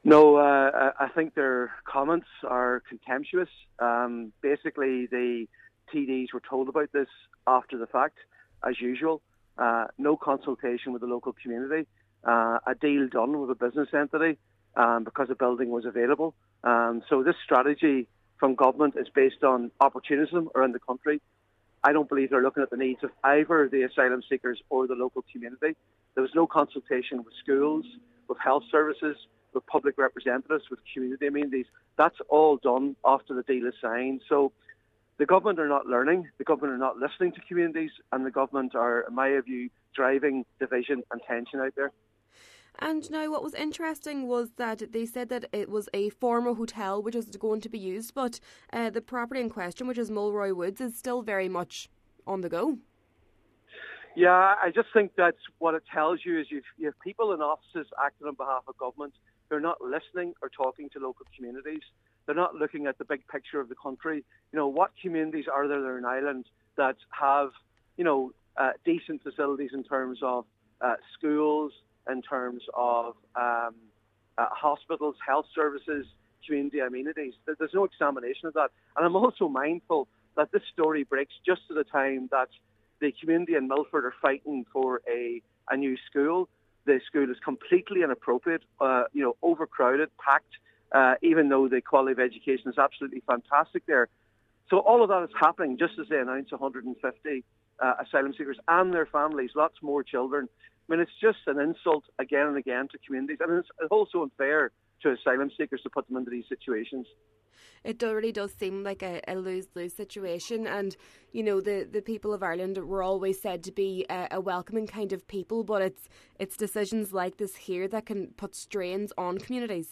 Deputy MacLochlainn says the news comes at a time when campaigns are ongoing for a new school in Milford to cope with the current population: